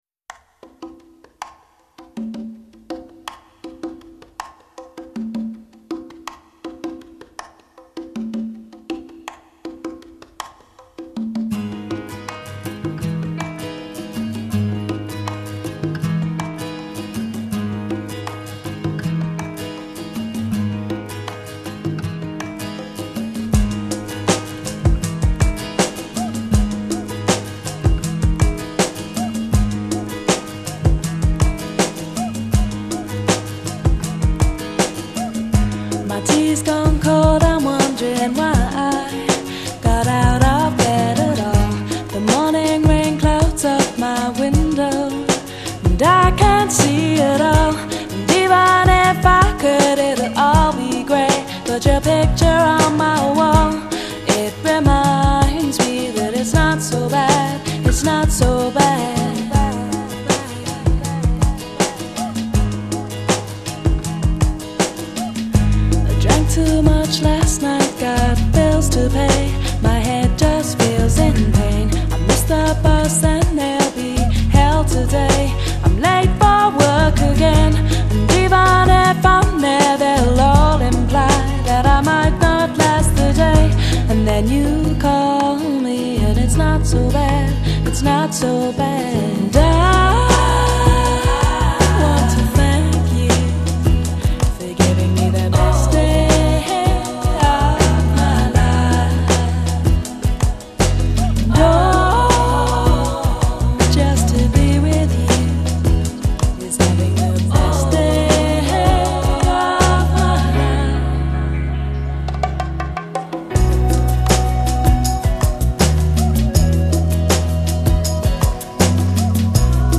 是一张结合流行／爵士／民谣／电子的精彩作品
她时而饱满细致时而如空气般飘逸的嗓音是无人能比的。